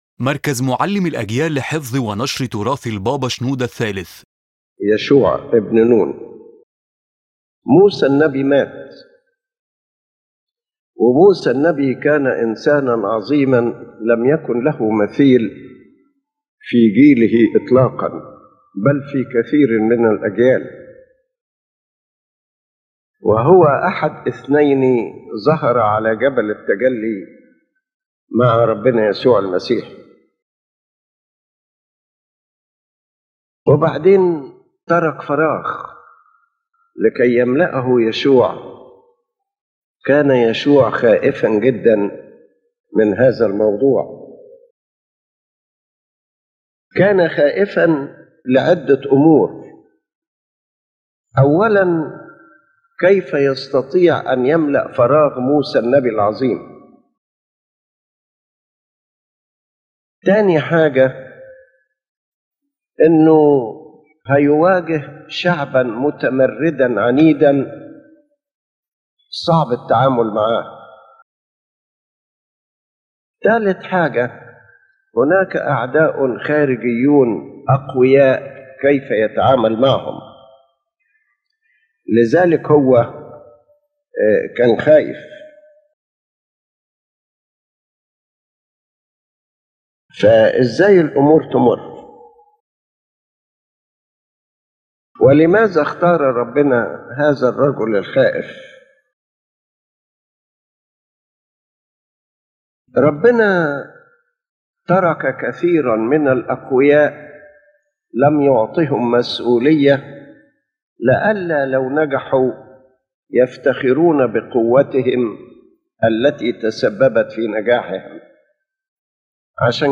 His Holiness Pope Shenouda III spoke about Joshua son of Nun, the disciple of Moses the Prophet, who succeeded him as the leader of the people of Israel after Moses’ death.